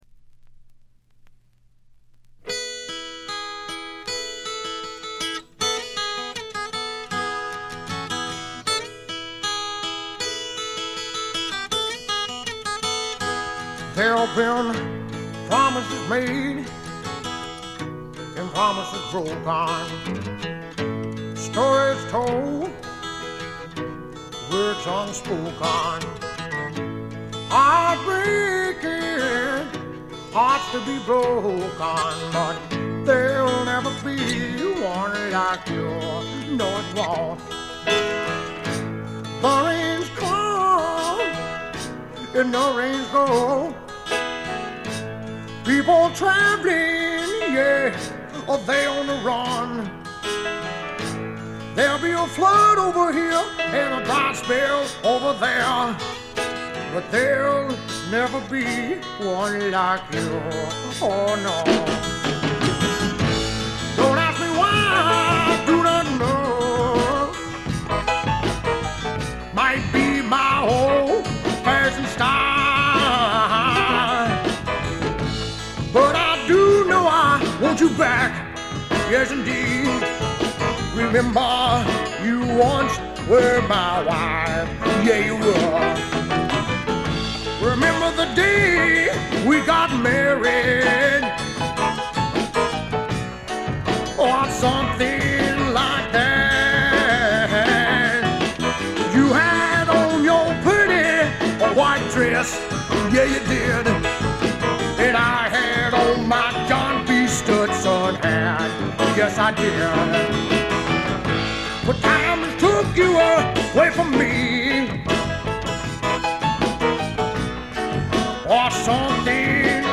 部分試聴ですが、ほとんどノイズ感無し。
肝心の音はといえば南部の湿った熱風が吹きすさぶ強烈なもの。
試聴曲は現品からの取り込み音源です。